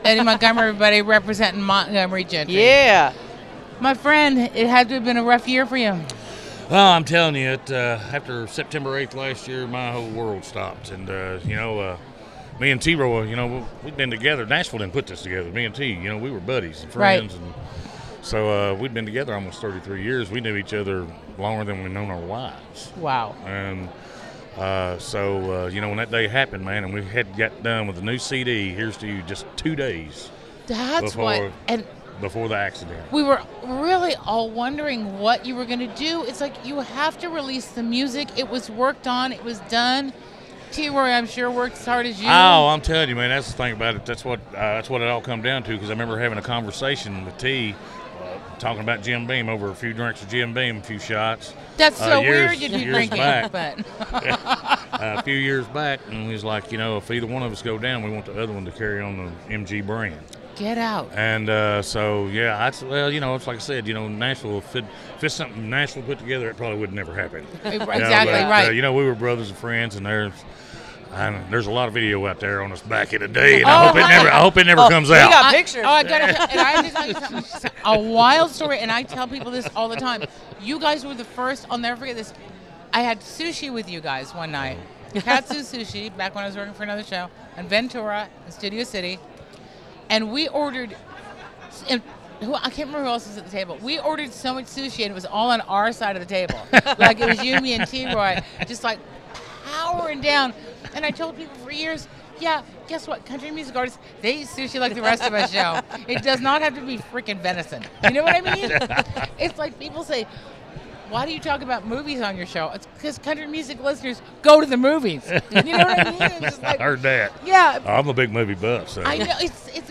Montgomery Gentry Interview At 2018 ACMs!